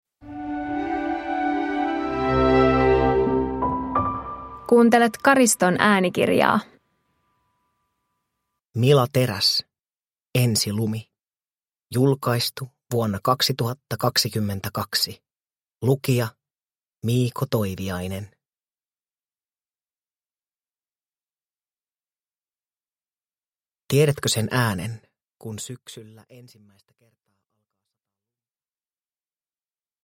Ensilumi – Ljudbok